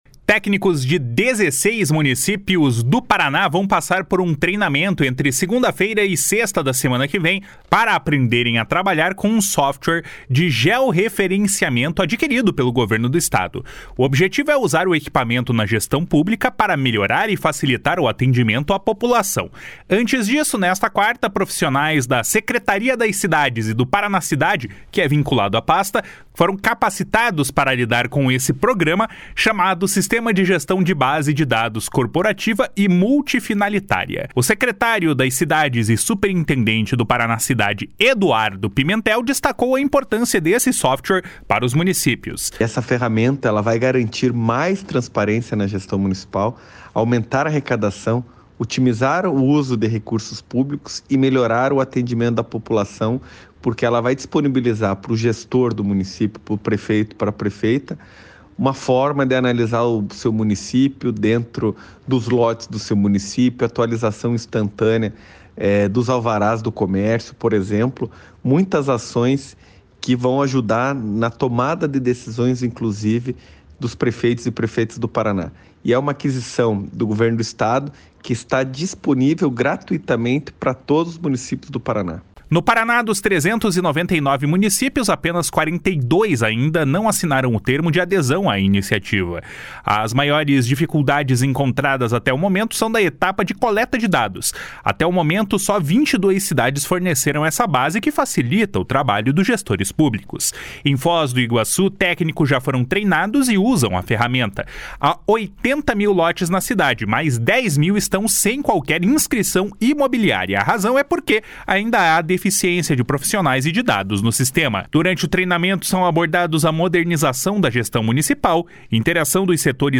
O secretário da pasta e superintendente do Paranacidade, Eduardo Pimentel, destaca a importância desse software para os municípios. // SONORA EDUARDO PIMENTEL //